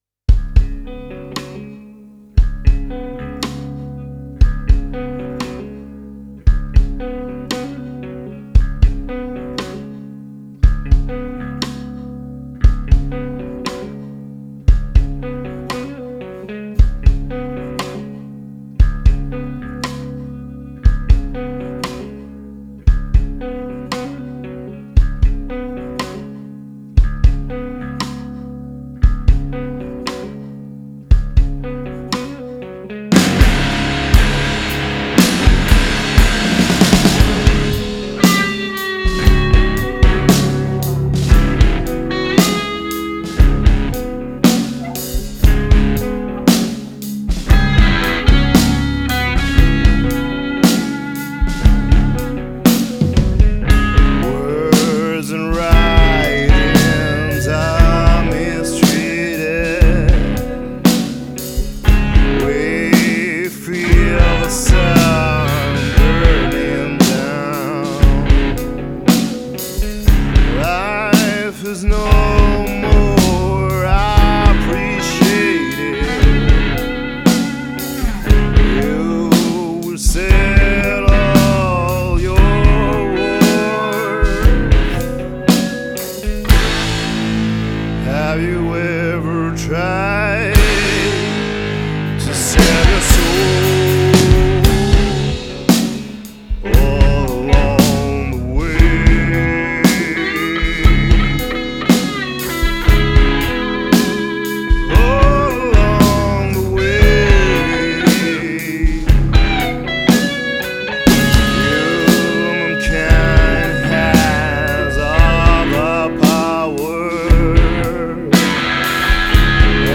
c’est la formation du duo batterie guitare